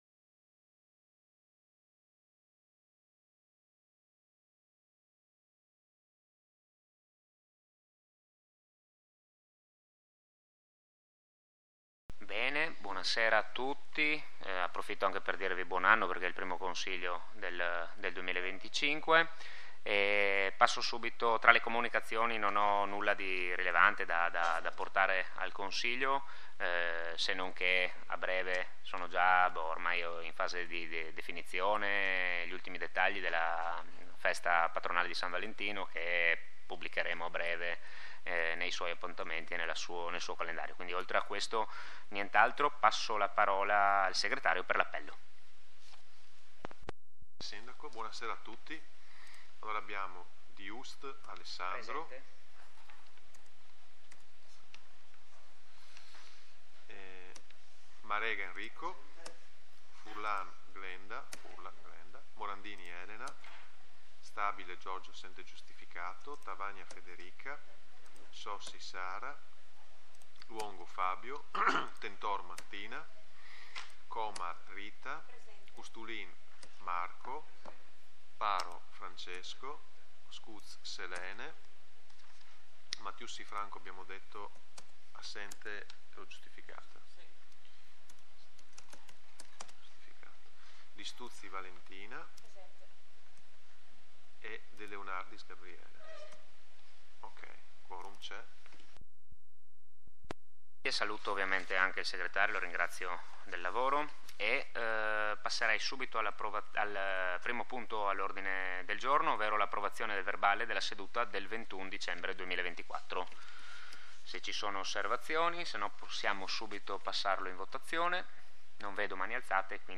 Registrazione audio del Consiglio comunale